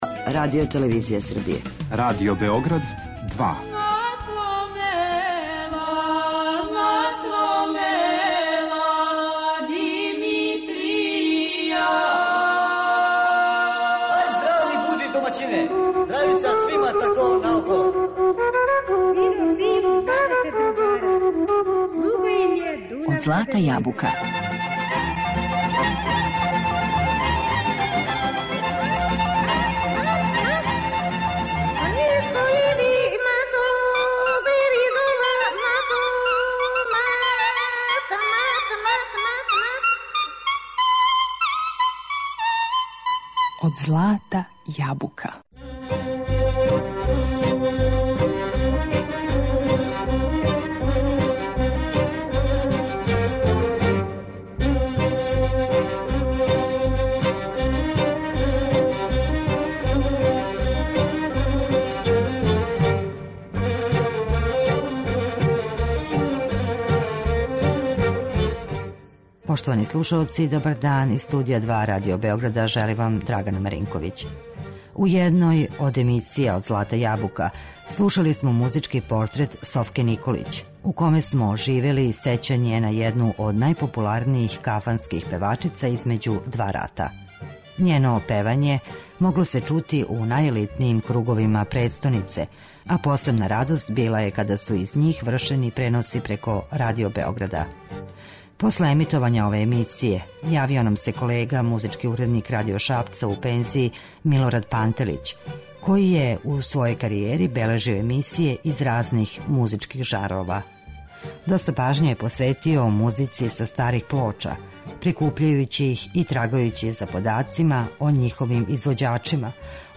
То је био и последњи интервју велике уметнице, а чућете га у данашњој емисији.